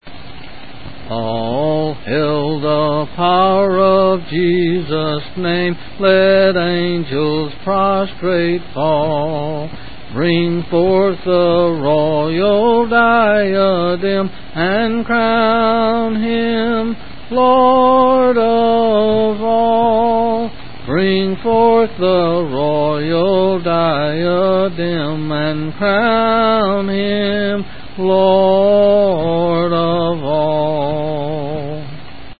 C. M.